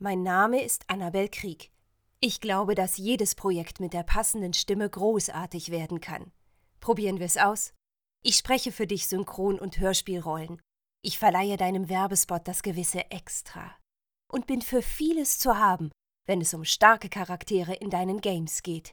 sehr variabel, hell, fein, zart, markant
Mittel minus (25-45)
Eigene Sprecherkabine
Presentation
Comment (Kommentar), Doku, News (Nachrichten), Narrative, Presentation, Off, Overlay, Tutorial